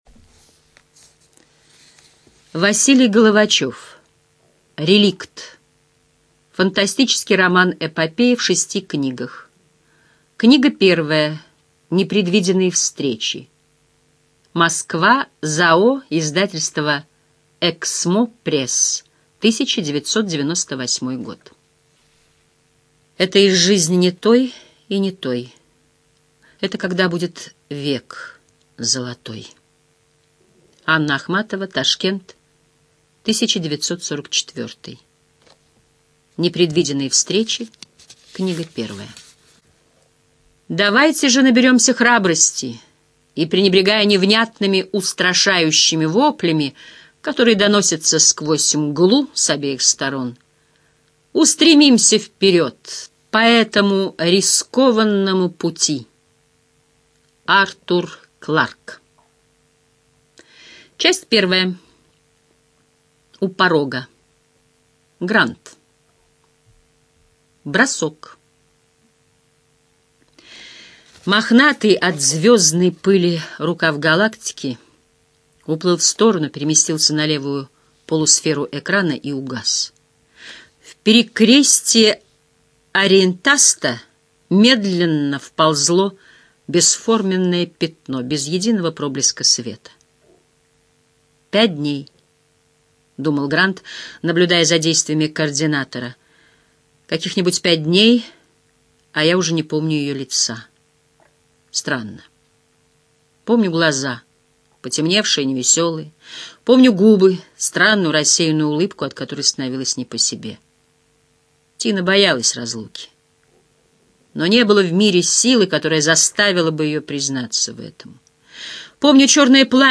ЖанрФантастика
Студия звукозаписиЛогосвос